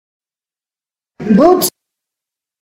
boet_mot.mp3